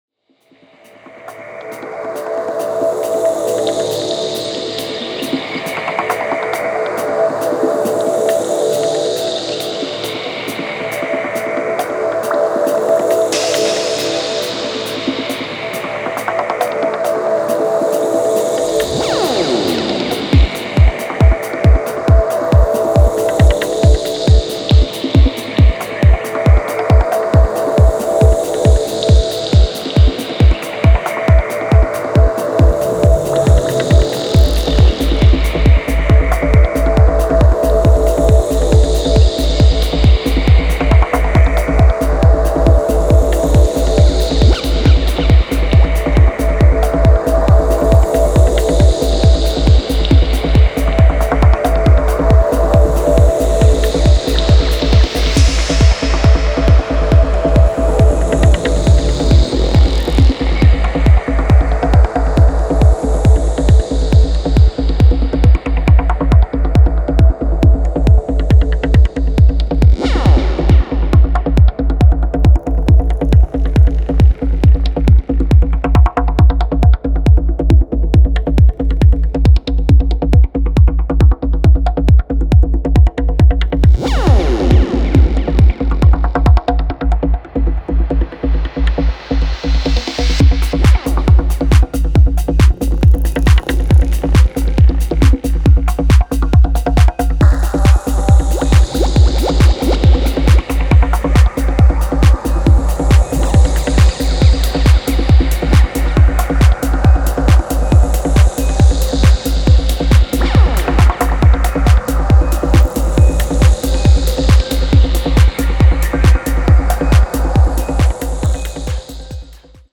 ダークに蠢くローエンドを基調に
ダビーなパーカッションが絶妙な
タイトでテンション高いグルーヴが揃いました。